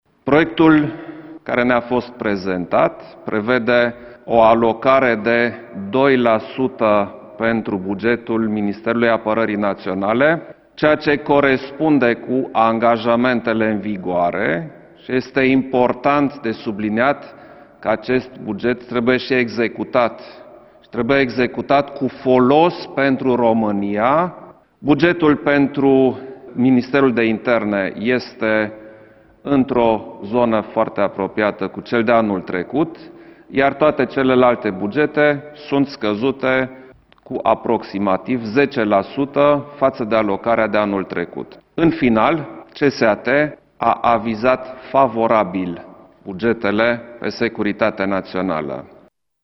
Preşedintele Klaus Iohannis a anunţat, la finalul ședinței CSAT că proiectele de buget ale instituţiilor cu atribuţii în domeniul siguranţei naţionale au fost avizate favorabil: